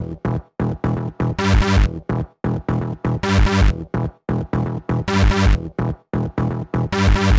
Tag: 128 bpm Electro Loops Synth Loops 2.52 MB wav Key : A